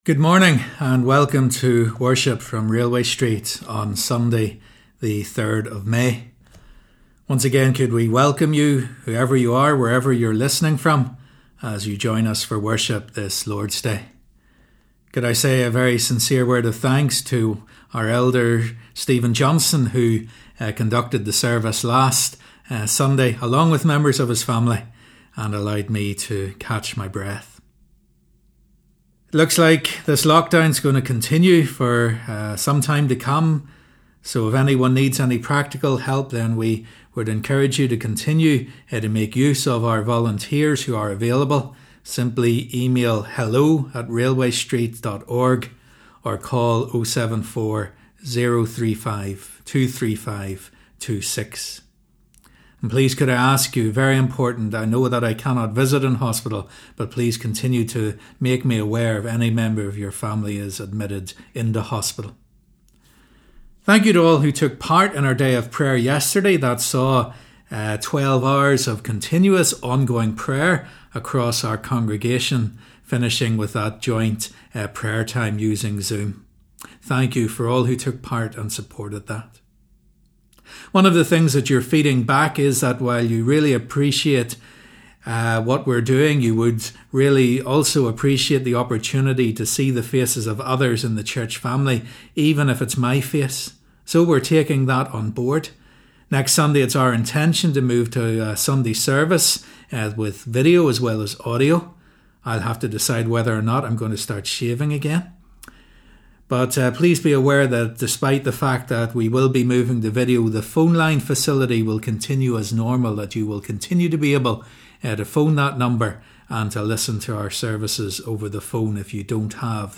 Sunday 3rd May 2020 Morning Service